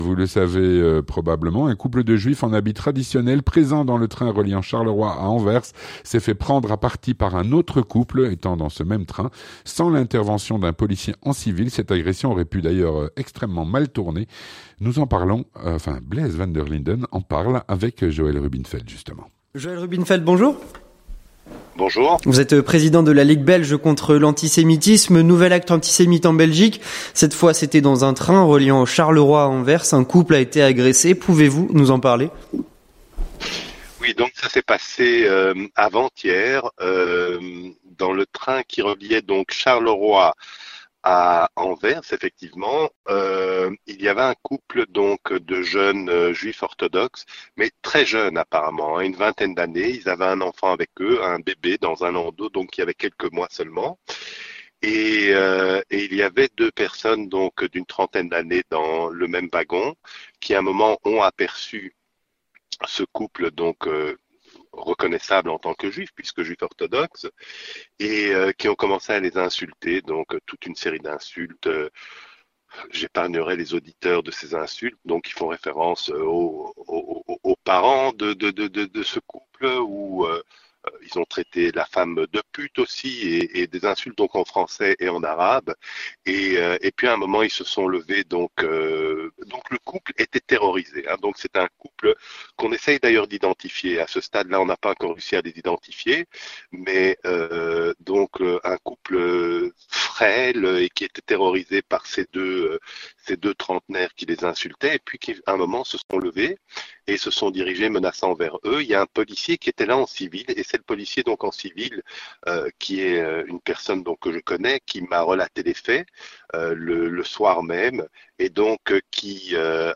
Présenté par